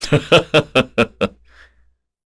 Chase-Vox_Happy2_kr.wav